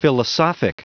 Prononciation du mot philosophic en anglais (fichier audio)
Prononciation du mot : philosophic